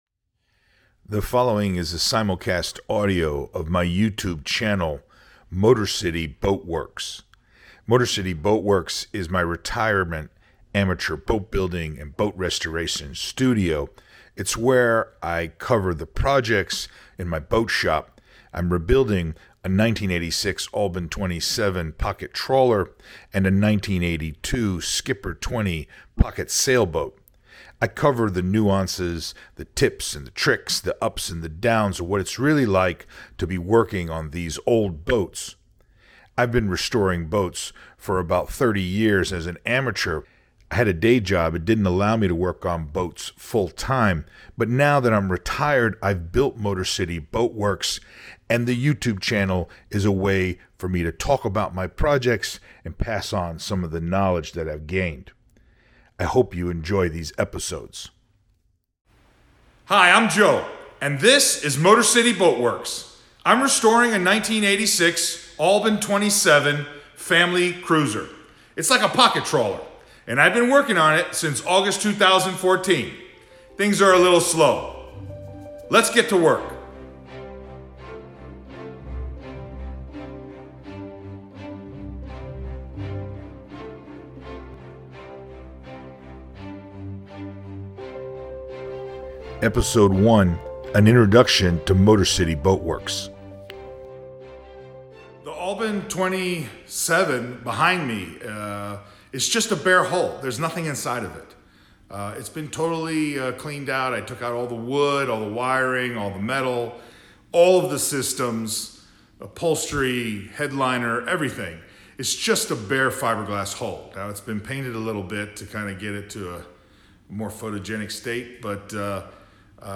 Podcast & Media Listen to Real Boat Building and Boat Restoration Now Playing Apple Podcasts Simulcast audio from the YouTube episodes with behind the scenes commentary and extra information.